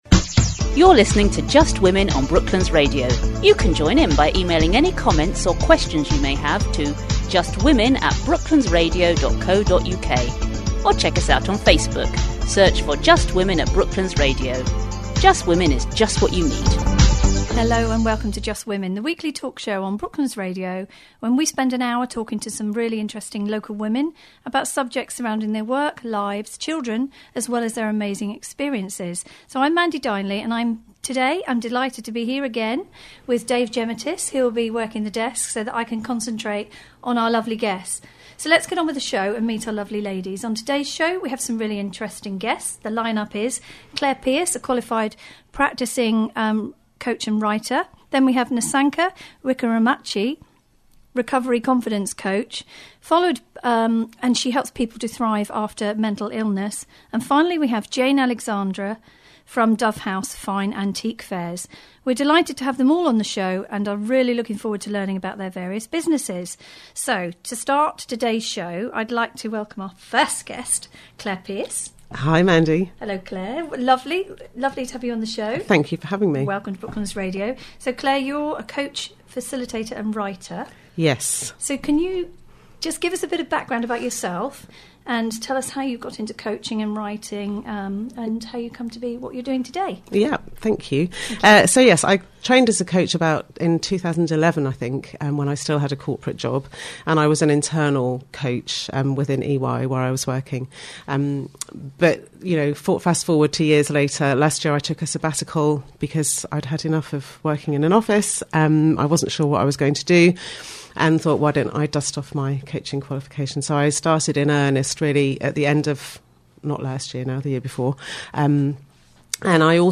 Chatting on "Just Women" programme on Brooklands Radio, 4 February 2020